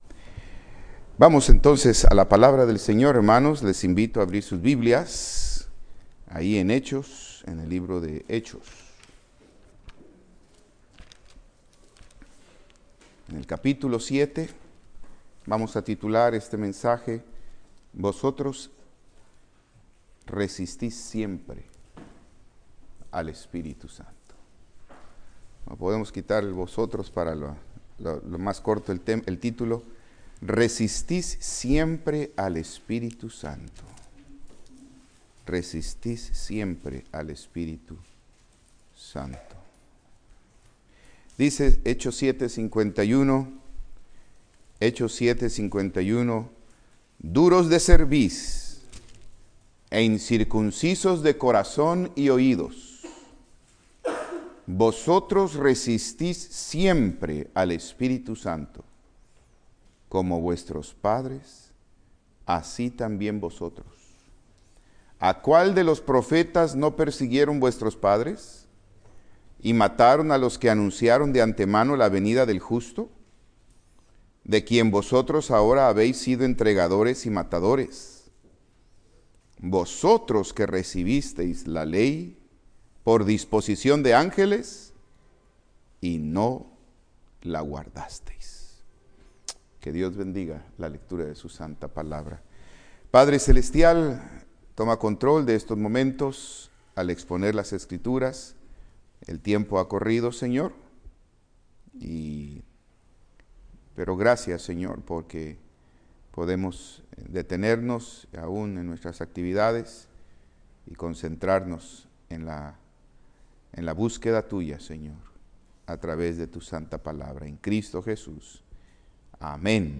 Servicio Vespertino